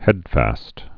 (hĕdfăst)